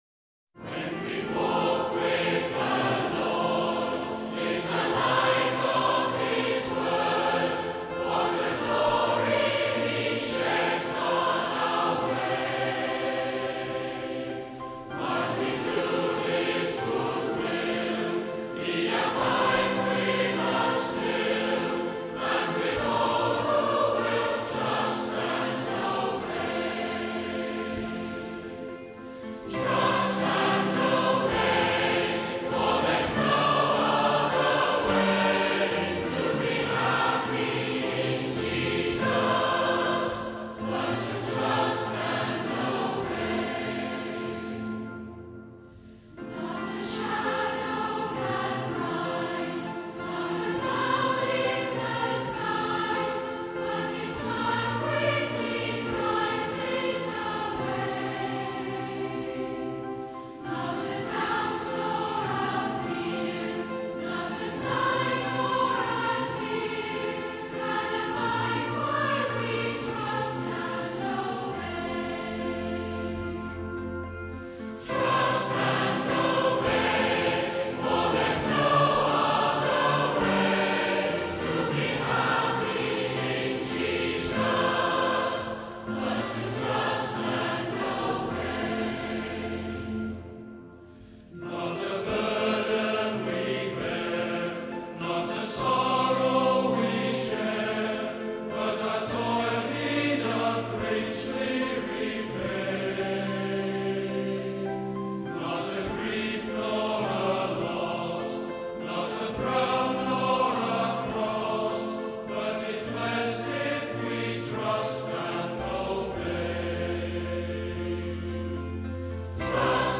This simple and powerful Christian hymn that many of us have sung in Sunday School and church services was written by John H. Sammis in 1887. This hymn illustrates the rewards of trusting the Almighty’s word and obeying His supreme will.